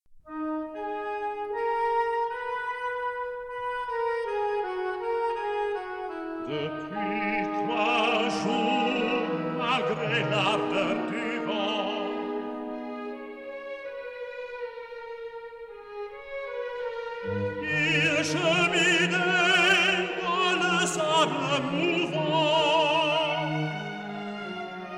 The musical accompaniment is suitably anguished.
Stereo recording made in London